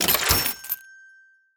points_sound_effect.mp3